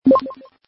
点击按钮音效.mp3